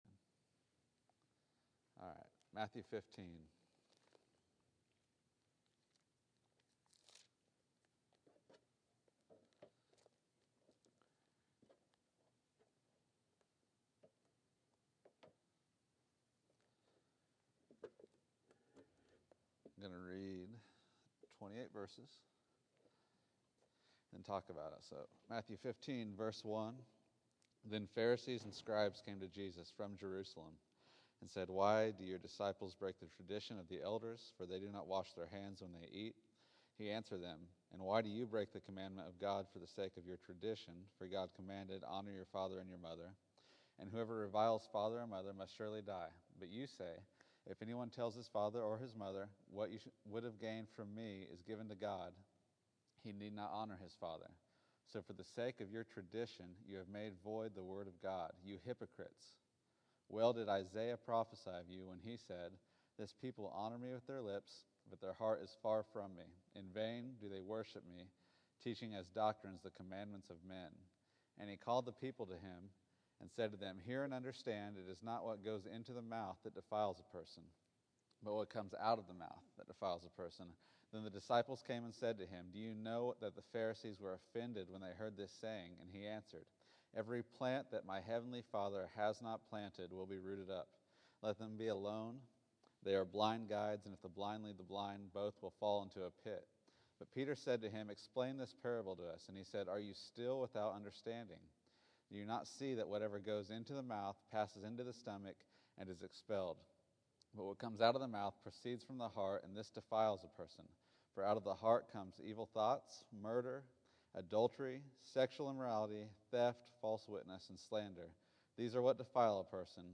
Matthew 15:1-28 October 13, 2013 Category: Sunday School | Location: El Dorado Back to the Resource Library Putting tradition over law leads to hypocrisy. Then a pagan Gentile woman pleases the Lord with her faith.